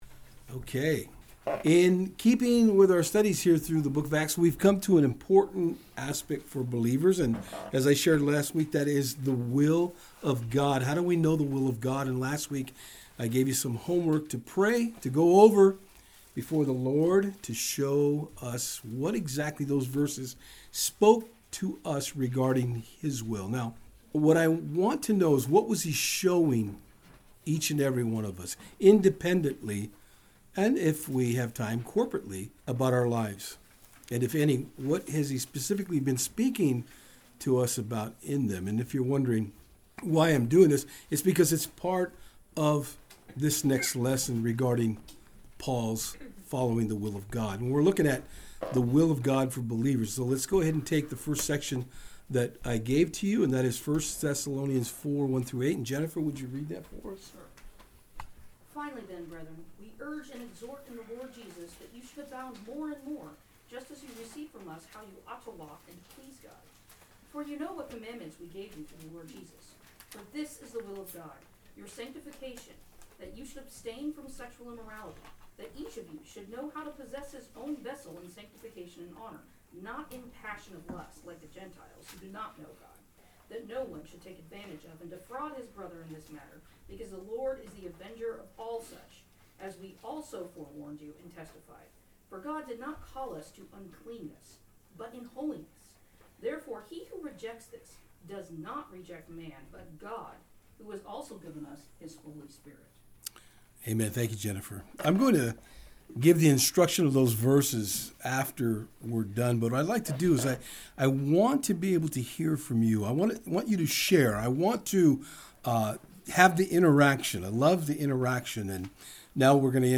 Service Type: Saturdays on Fort Hill
In this message you will hear the first 32 minutes of personal testimony of what the Lord has been showing some of the folks at the study regarding specific verses pertaining to “The Will of God.”